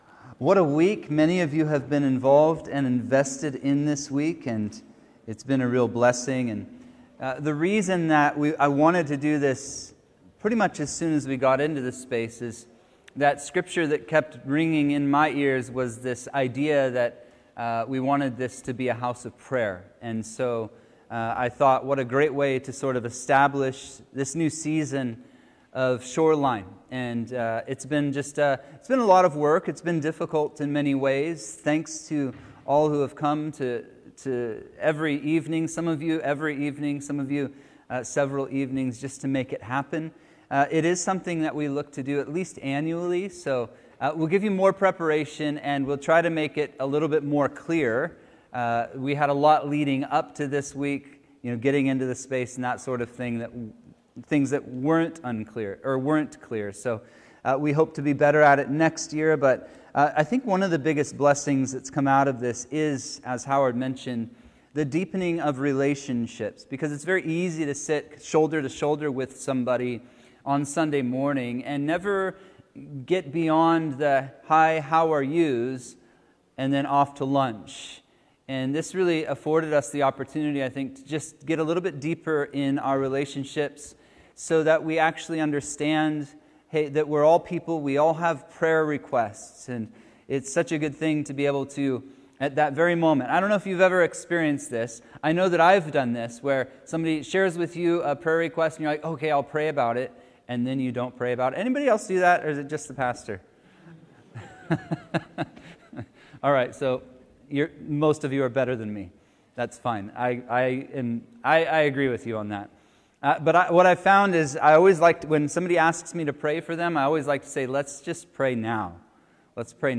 Sermons | Shoreline Calvary